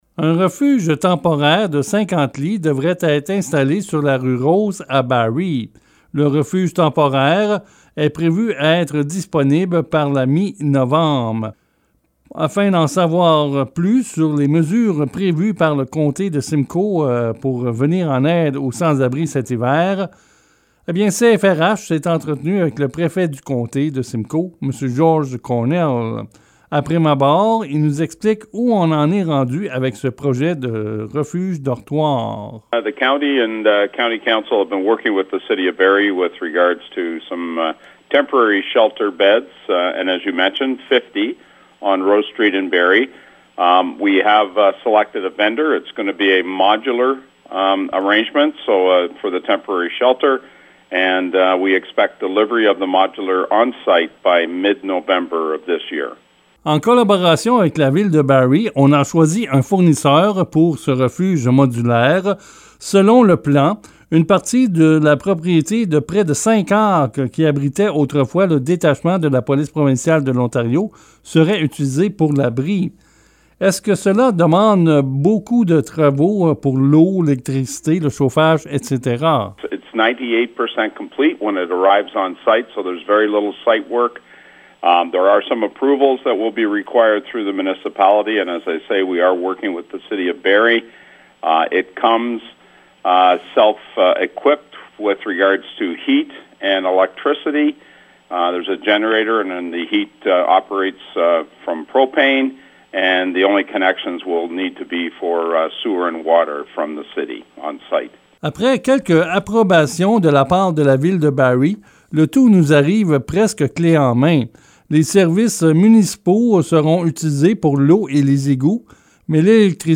Afin d'en savoir plus sur ce refuge, CHFR a questionné le préfet du comté George Cornell.